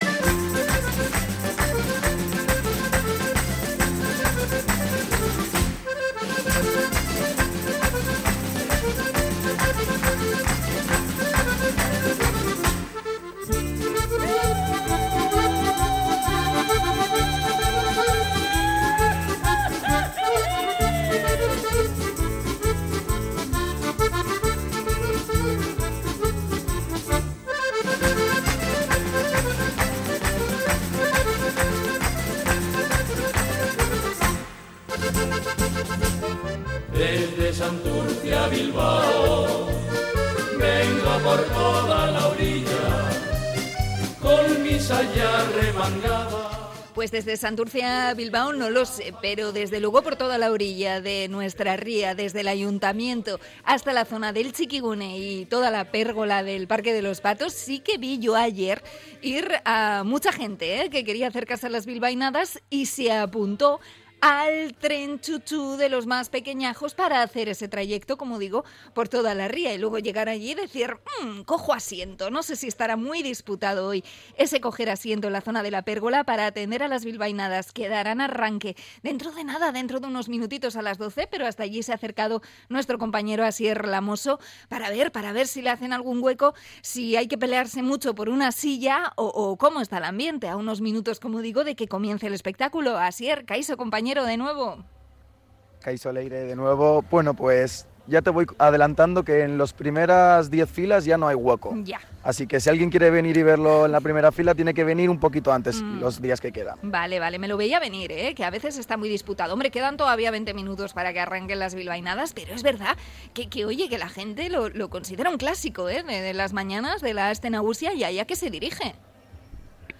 Conexión desde la Pérgola
Nos pasamos por el ambiente previo a las bilbainadas: las sillas escasean y la gente se prepara para cantar
Ambiente en la Pérgola / Radio Popular de Bilbao - Herri Irratia